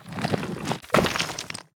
RockBreak.wav